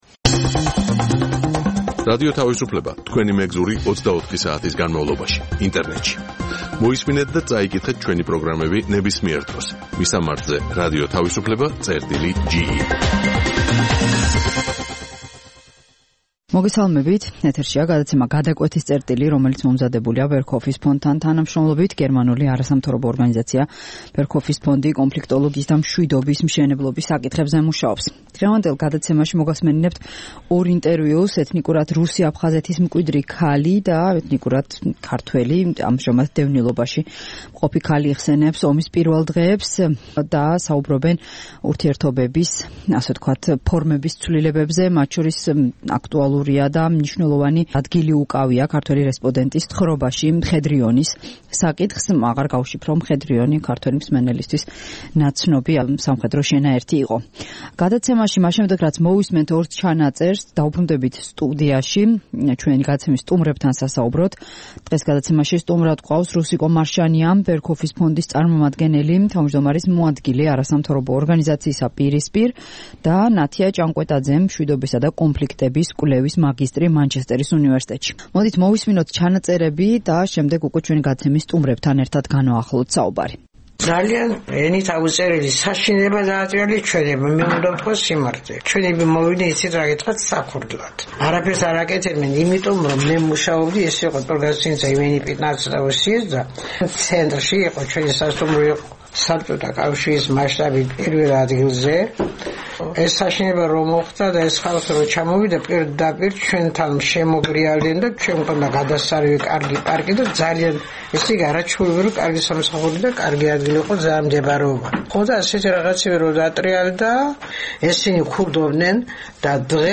ეთნიკურად ქართველი და ეთნიკურად რუსი აფხაზეთის მკვიდრი ორი ქალი ჰყვება აფხაზეთში „მხედრიონის“ შესვლისა და ადგილობრივ მოქალაქეებთან მათი ურთიერთობის შესახებ.